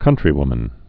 (kŭntrē-wmən)